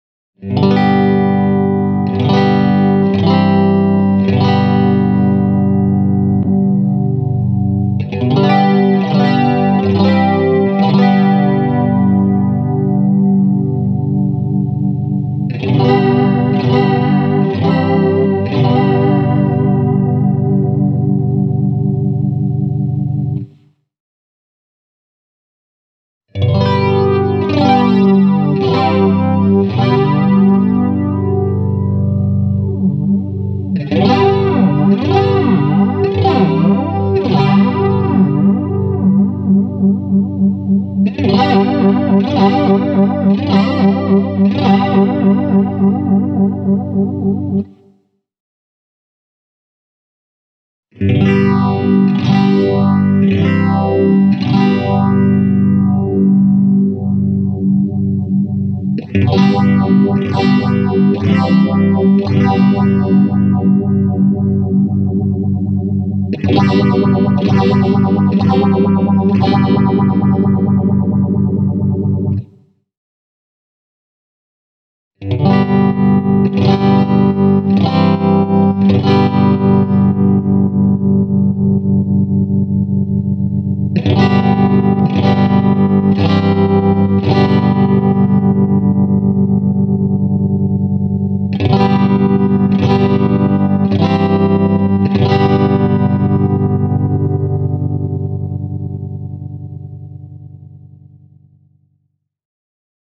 The Modulation control lets you choose and adjust one of four different modulation effects – chorus, flanger, phaser (the abbreviation ”ORG” stands for the colour orange) and tremolo.
Here’s an audio clip running through the four modulation types, starting with the modulation section off: